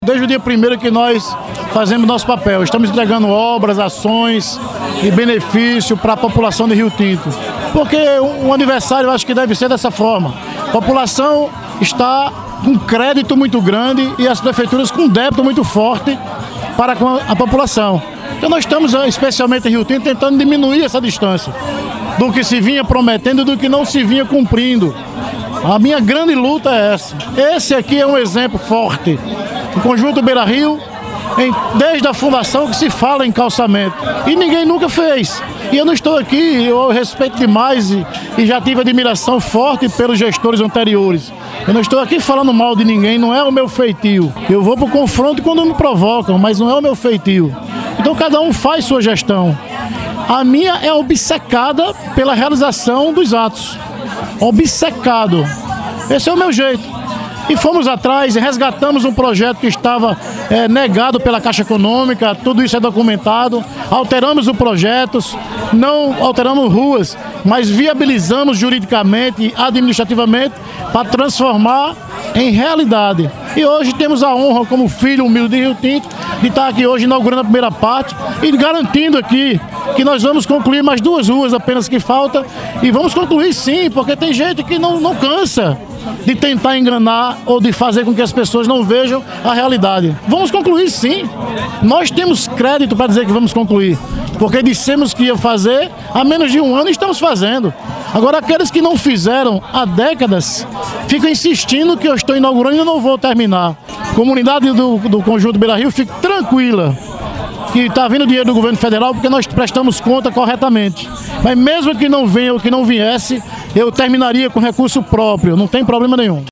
Na solenidade de inauguração, na terça-feira (5), em seu discurso o prefeito Fernando Naia, agradeceu a Deus, a equipe de governo e a confiança dos seus conterrâneos, que lhe deram a oportunidade de administrar os destinos do município por um mando de quatro anos.